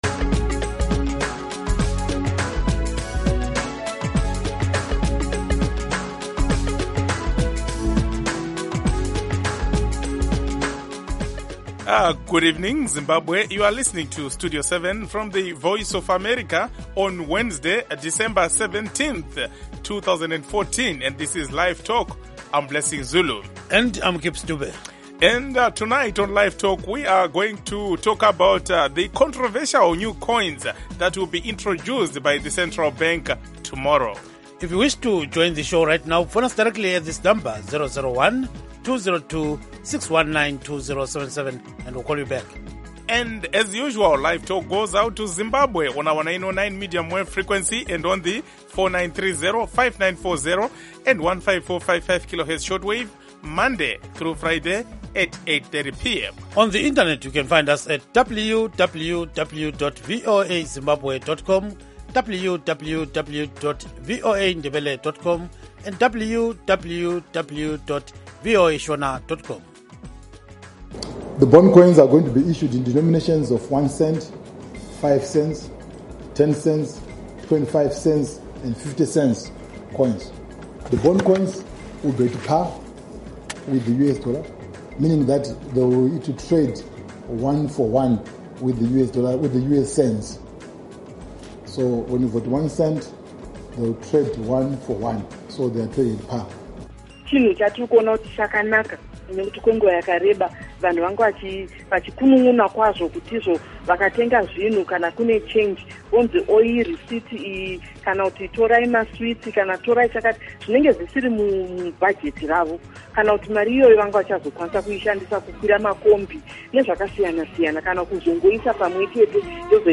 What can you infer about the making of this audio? Zimbabweans living outside the country who cannot receive our broadcast signals can now listen to and participate in LiveTalk in real time.